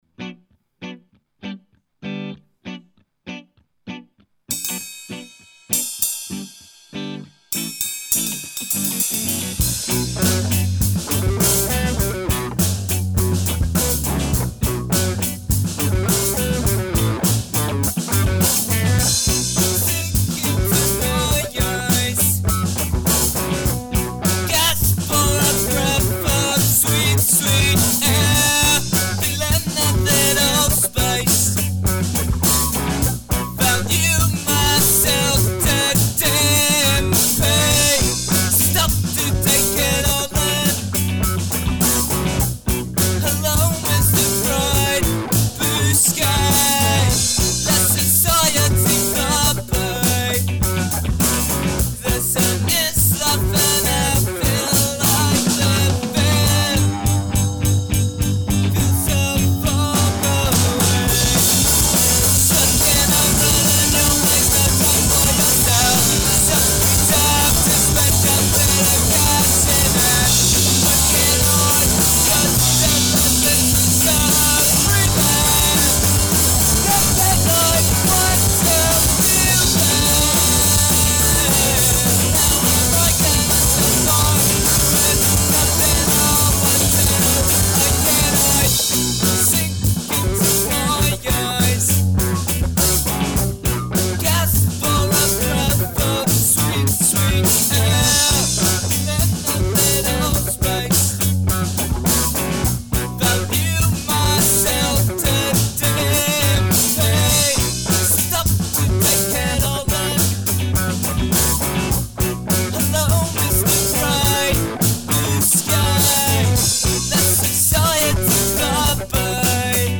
2007 Recorded at Riseholme Grange